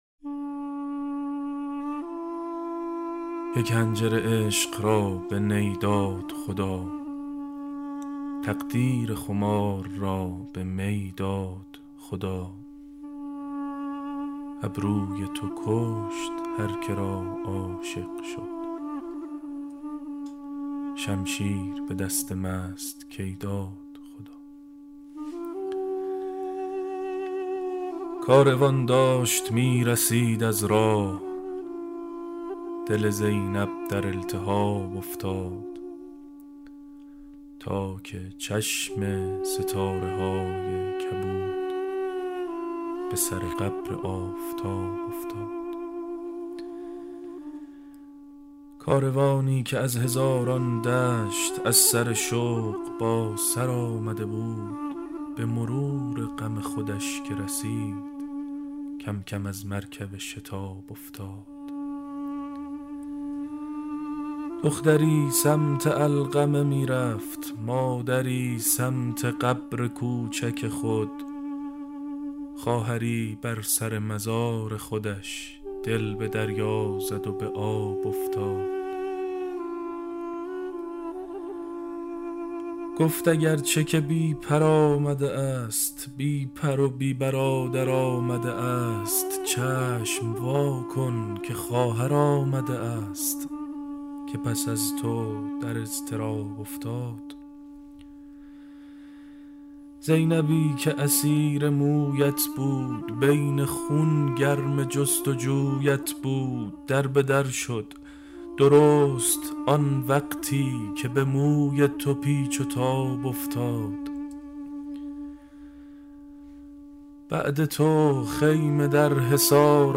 بسته صوتی طریق اشک شعر خوانی شاعران آیینی (بخش سوم)
بسته صوتی طریق اشک مجموعه شعر خوانی تعدادی از شاعران اهل بیت است که به همت استودیو همنوا و با حمایت خانه ی موسیقی بسیج تهیه شده که در ایام اربعین از رادیو اربعین پخش خواهد شد.